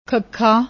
noun, masculineseventh letter of Gurmukhi script used to represent voiceless aspirated velar plosive sound [kh]